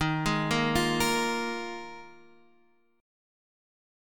D#sus2sus4 chord